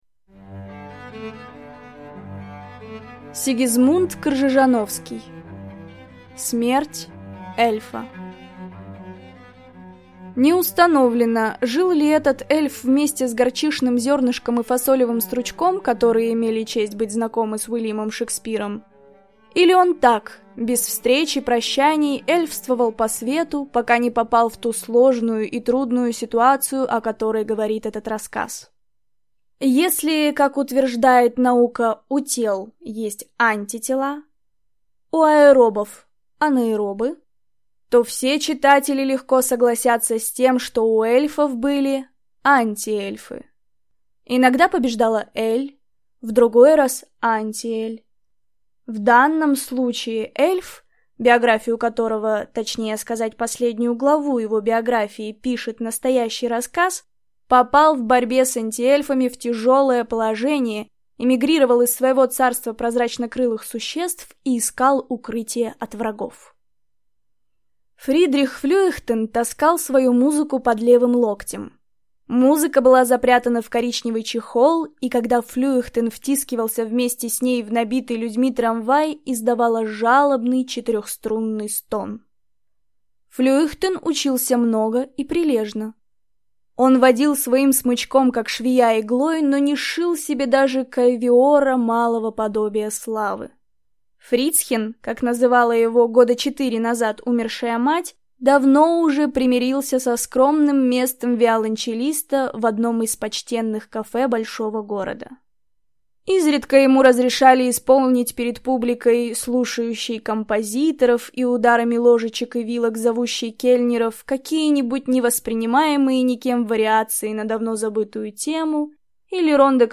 Аудиокнига Смерть эльфа | Библиотека аудиокниг